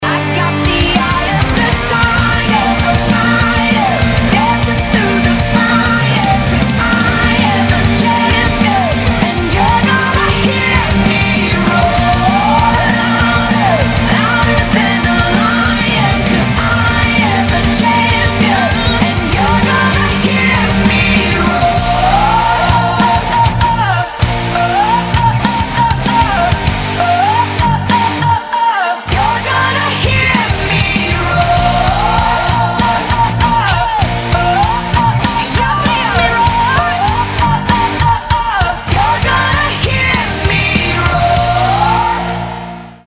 • Качество: 192, Stereo
громкие
женский вокал
заводные
Pop Rock
бодрые
легкий рок
Эта неповторимая песня теперь и в рок-исполнении.